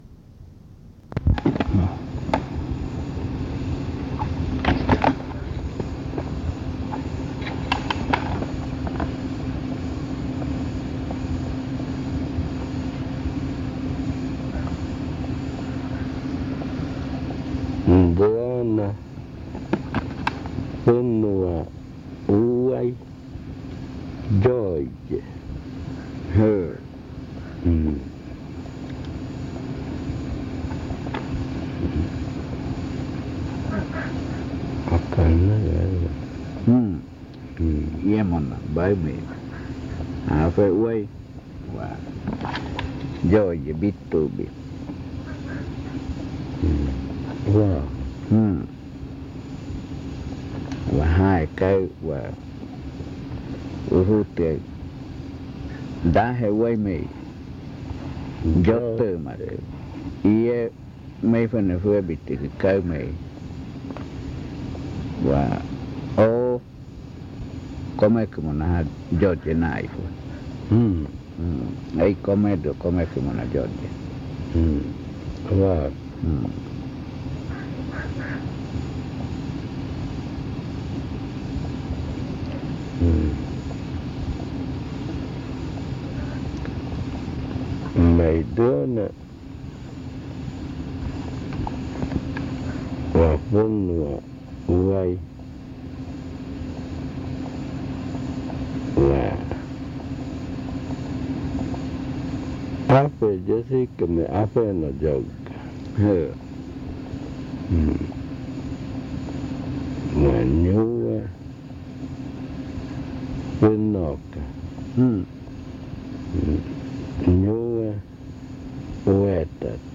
Esta grabación contiene la explicación y el rezo de la conjuración para hacer tabaco.